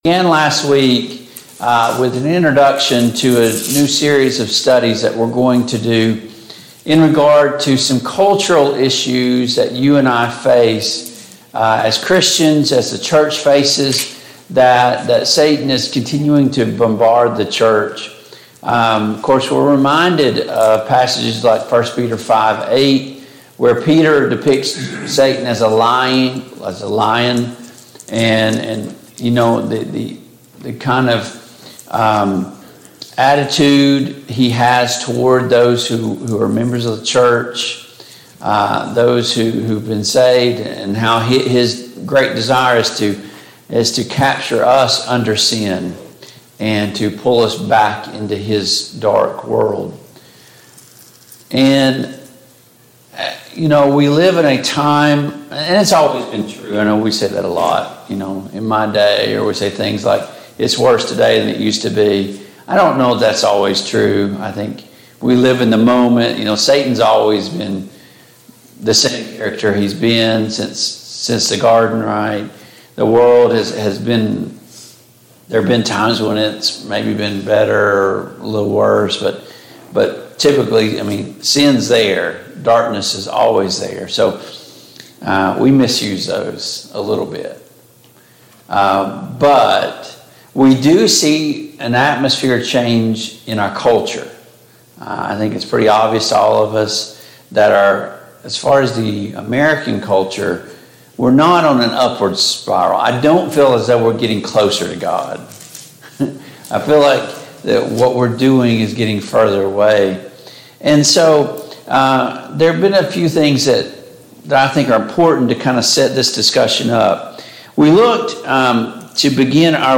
Service Type: Sunday Morning Bible Class